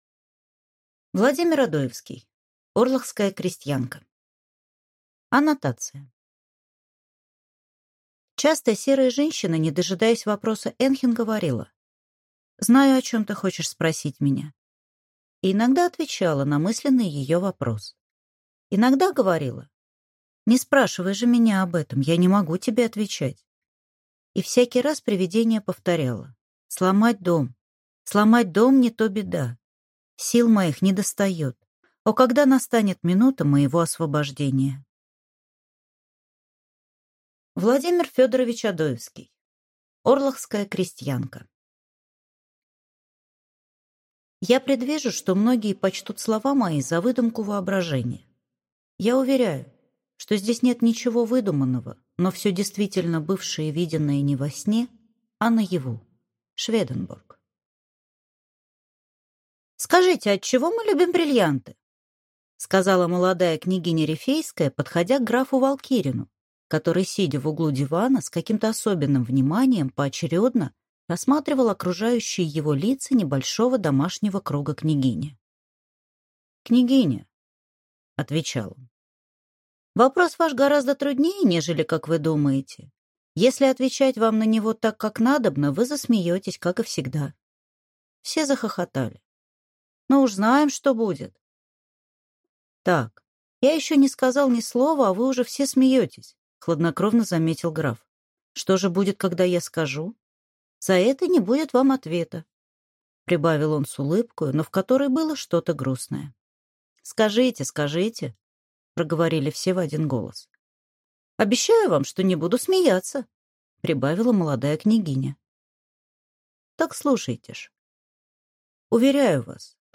Аудиокнига Орлахская крестьянка | Библиотека аудиокниг